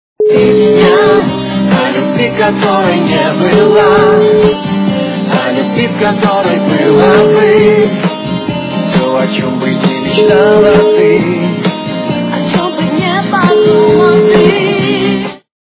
русская эстрада
качество понижено и присутствуют гудки.